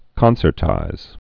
(kŏnsər-tīz)